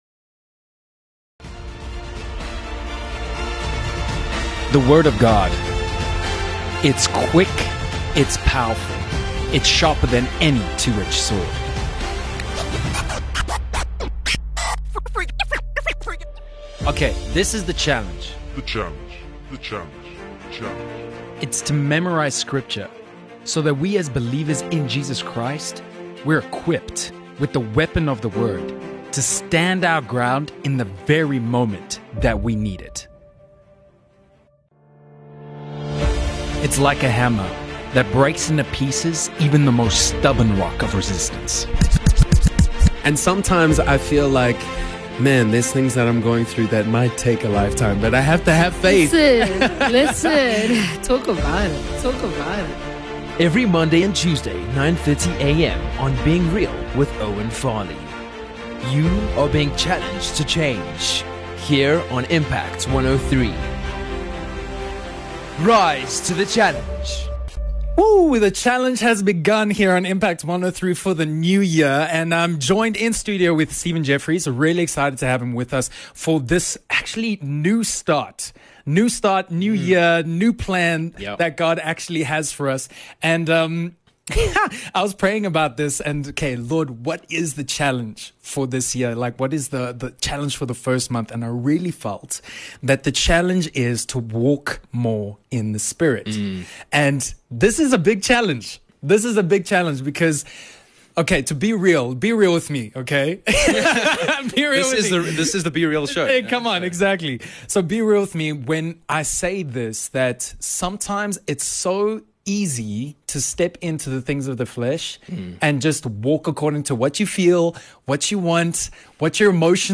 Filled with laughs, testimonies and the Word of God.